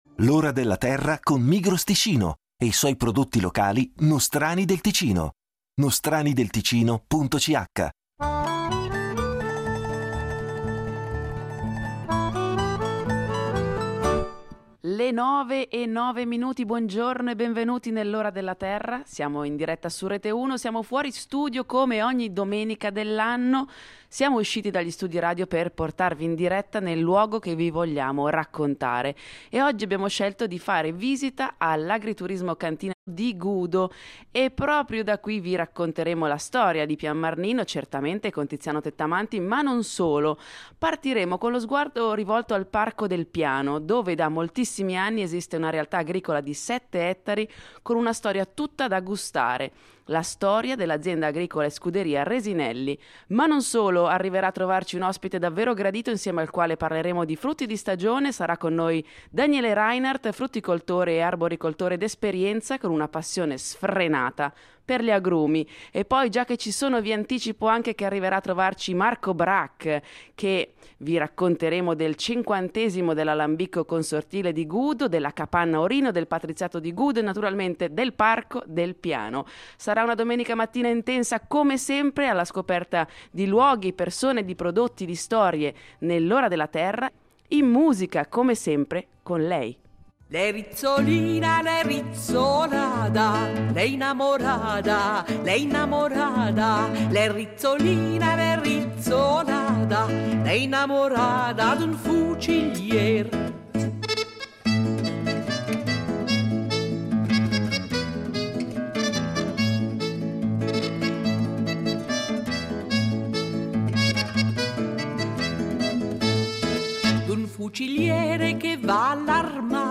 Vigna e cavalli, in diretta da Gudo
In diretta dall’agriturismo Pian Marnino, vi racconteremo di alcune perle agricole di questo quartiere di Bellinzona. Lo faremo conoscendo da vicino l’azienda agricola e scuderia Resinelli, inserita in una superficie agricola di 7 ettari nel Parco naturale del Piano di Magadino.